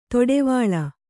♪ toḍevāḷa